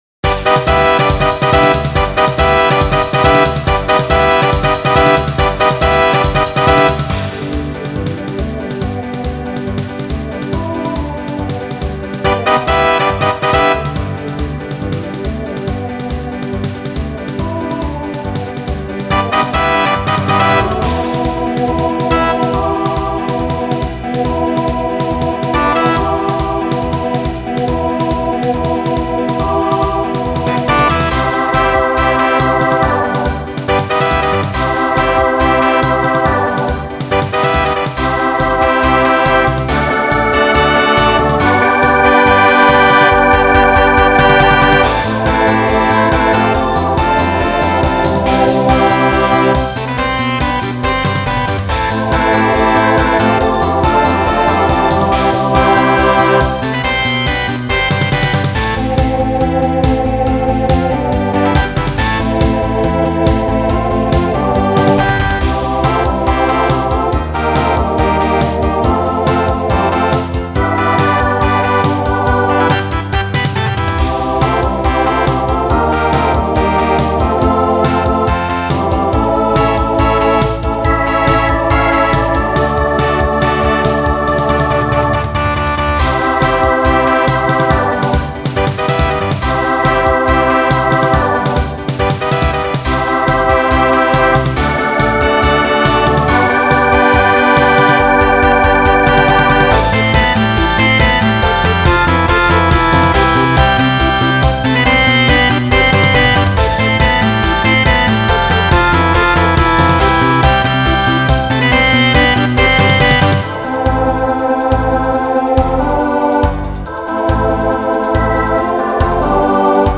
New SSA voicing for 2023.